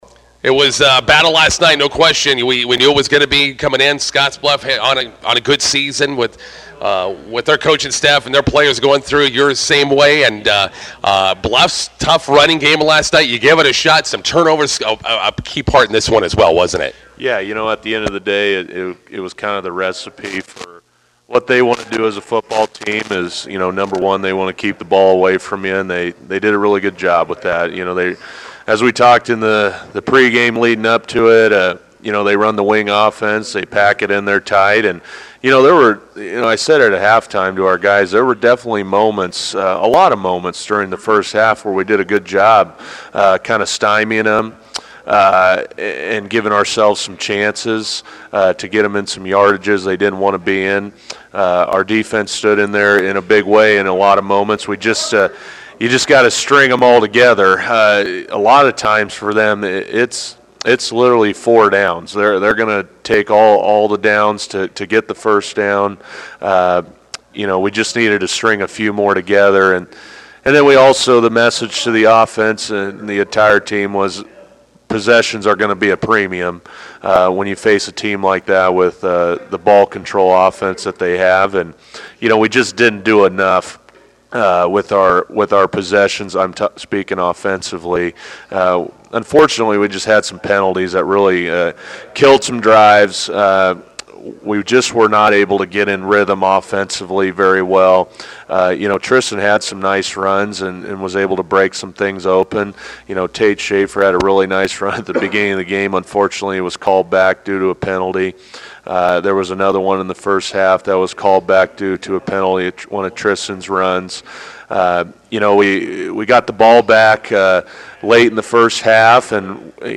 INTERVIEW: Scottsbluff hands the Bison second loss of the season, 21-7.